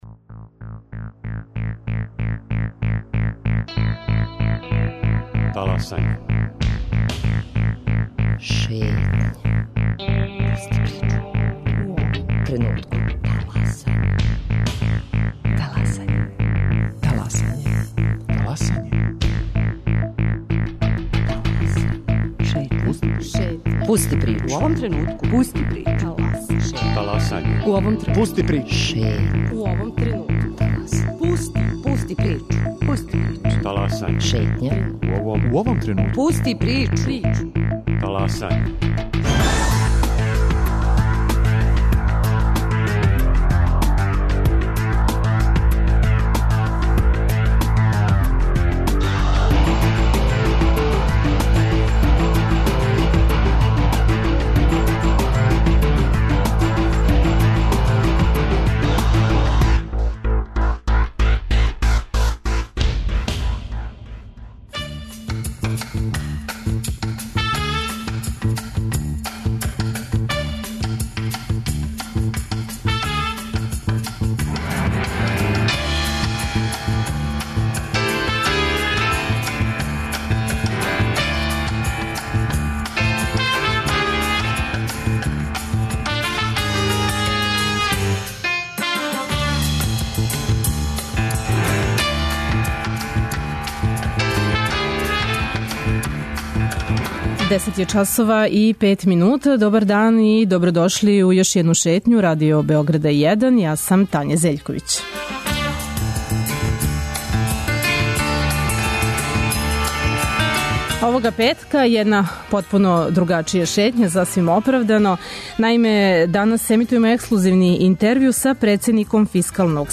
Зашто је једнократна исплата помоћи боље решење од повишица плата и пензија? Како смањити јавни сектор, а да се не уруше образовање, здравство или систем одбране земље? Како треба да изгледа буџет за 2016. годину? На наша питања одговара председник Фискалног савета Павле Петровић.